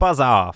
Voice Lines / Dismissive
buzz off.wav